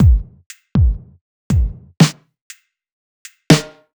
Loops
DL_Halftime.wav